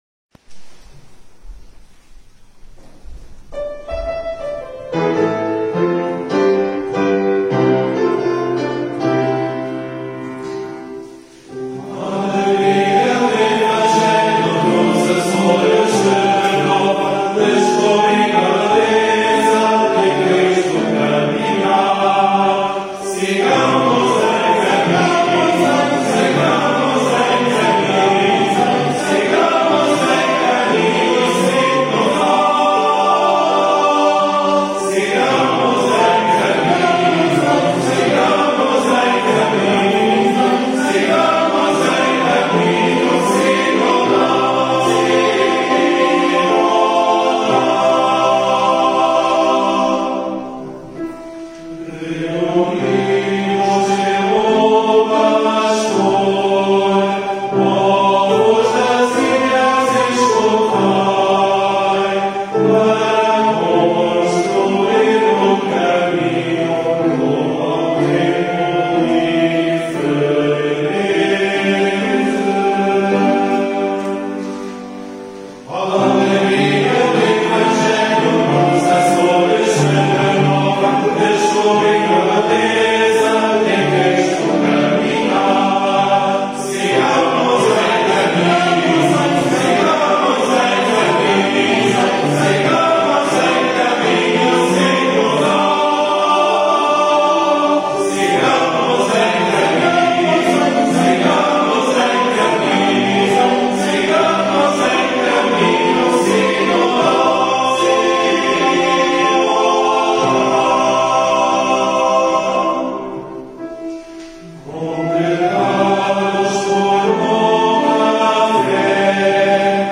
Pelo coro do Seminário de Angra.